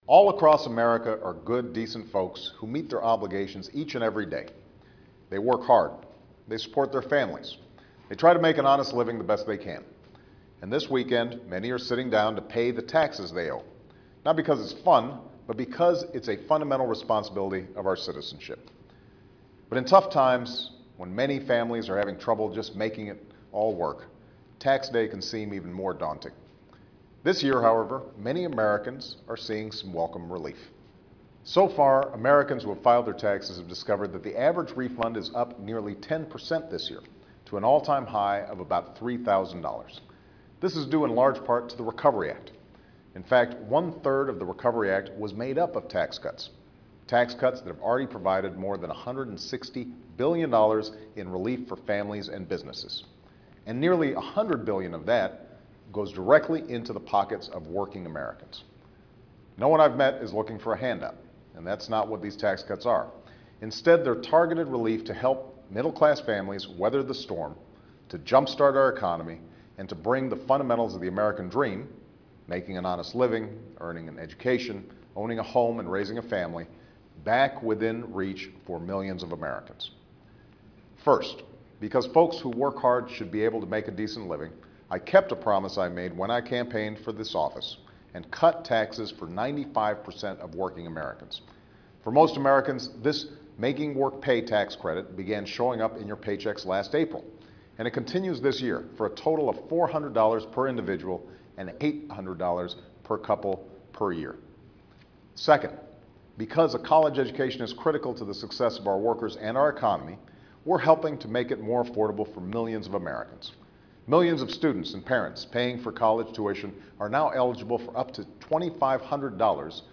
Barack Obama's weekly radio addresses (and some other speeches).